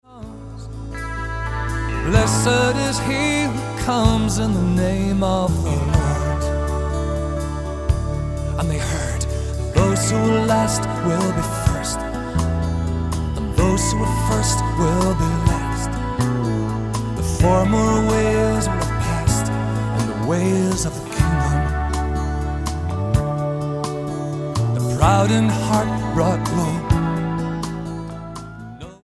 STYLE: Pop
acoustic guitar